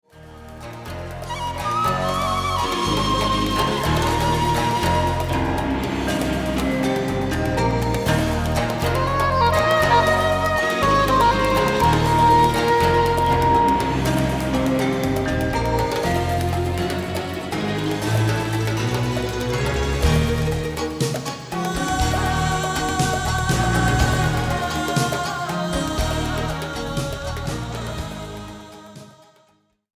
drums & percussion
piano, keys
flutes
female chorus:
men’s chorus: